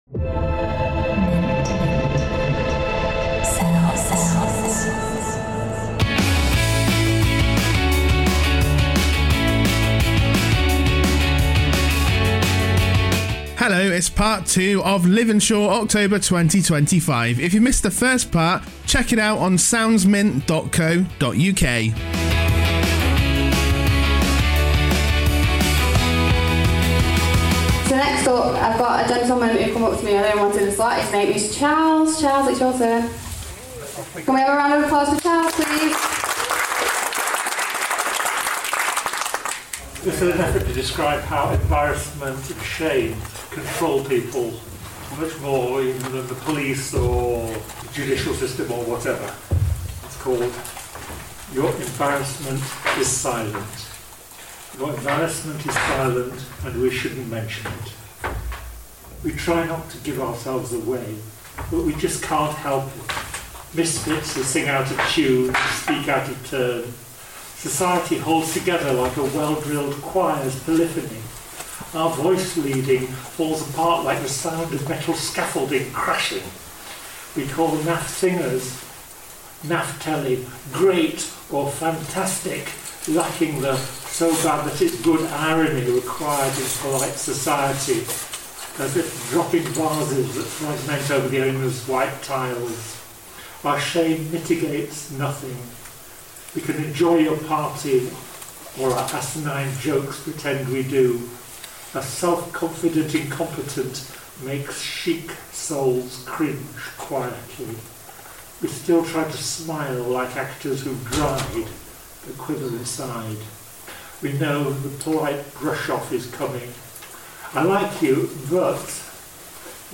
In October Liveinshawe hosted a poetry and spoken word event at the Wythenshawe Creative space. Mint sounds went along to record some of the performances of local people and their poetry.
Please be aware that this event contains strong language and sensitive themes.